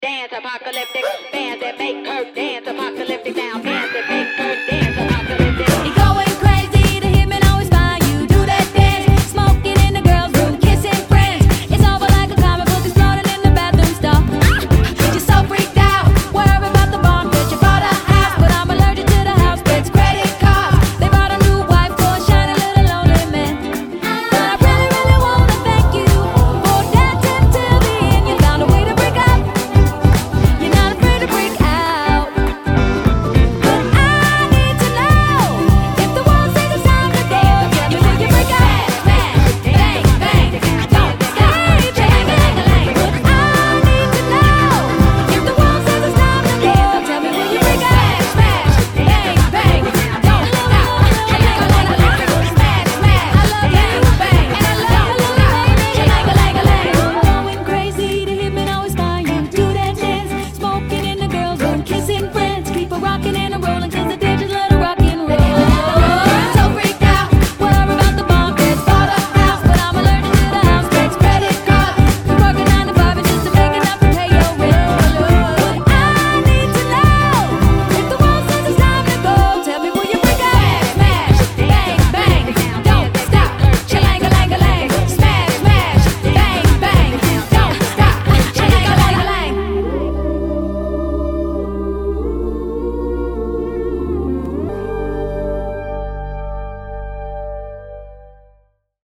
BPM206
Audio QualityPerfect (High Quality)
features swinging rhythms at a face pace